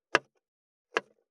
514切る,包丁,厨房,台所,野菜切る,咀嚼音,ナイフ,調理音,まな板の上,料理,
効果音厨房/台所/レストラン/kitchen食器食材